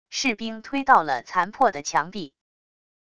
士兵推到了残破的墙壁wav音频